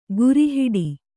♪ guri hiḍi